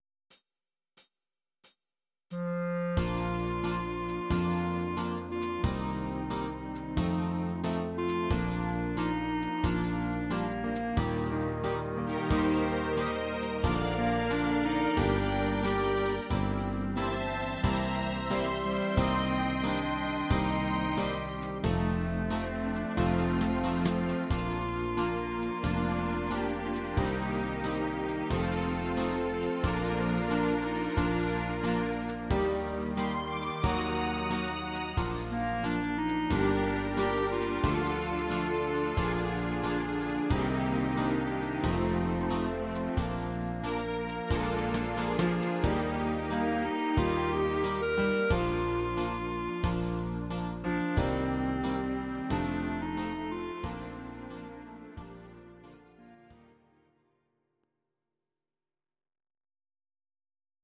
These are MP3 versions of our MIDI file catalogue.
Please note: no vocals and no karaoke included.
instr. clarinet